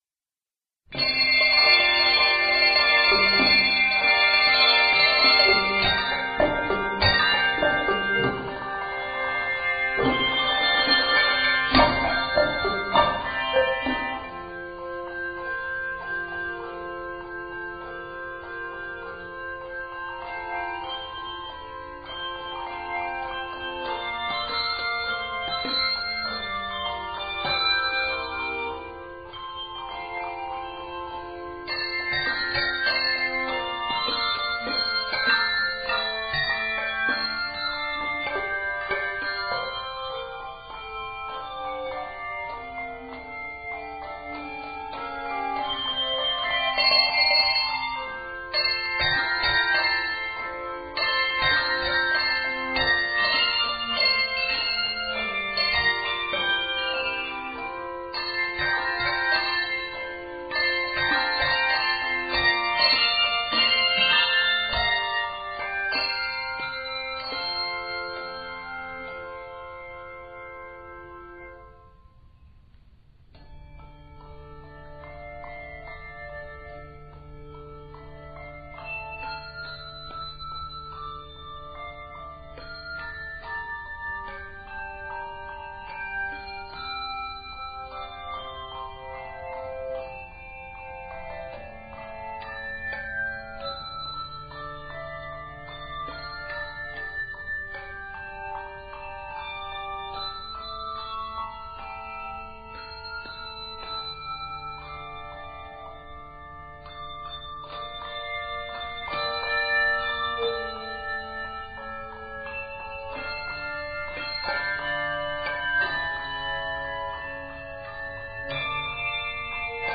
handbell choirs
melodic and rhythmic arrangement